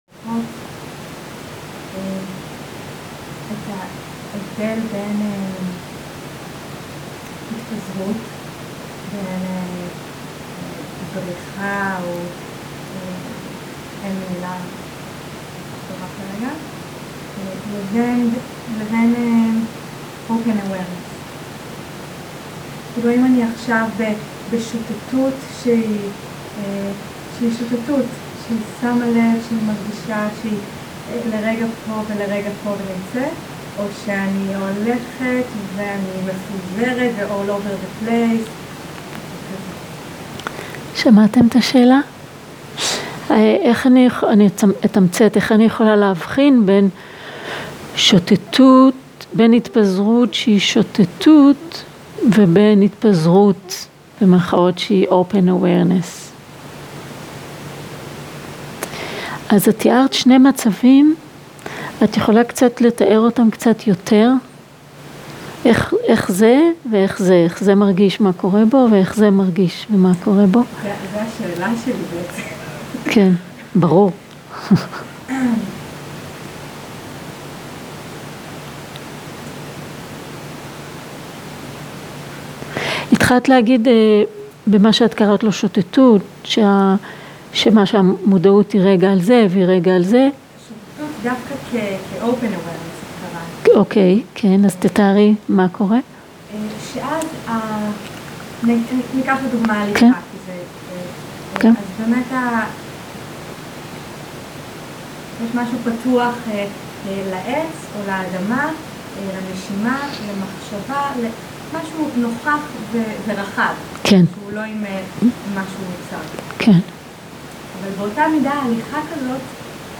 Dharma type: Questions and Answers שפת ההקלטה